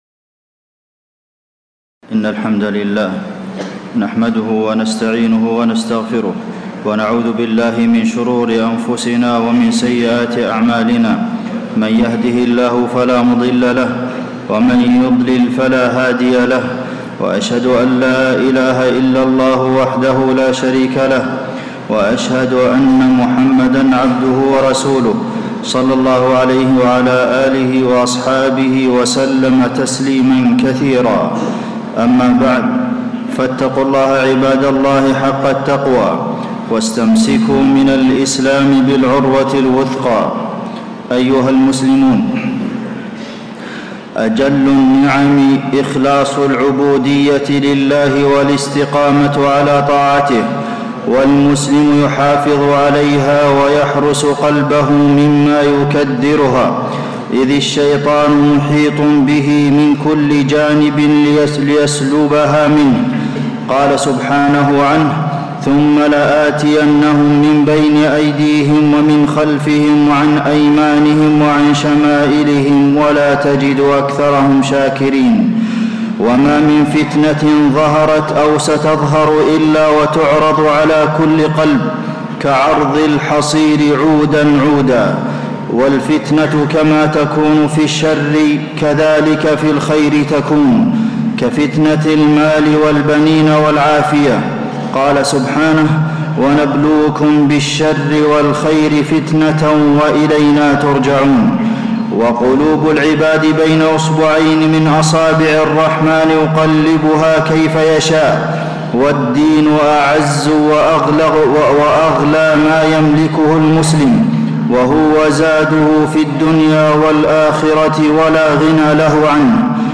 تاريخ النشر ٣ محرم ١٤٣٧ هـ المكان: المسجد النبوي الشيخ: فضيلة الشيخ د. عبدالمحسن بن محمد القاسم فضيلة الشيخ د. عبدالمحسن بن محمد القاسم الفتن وثبات القلب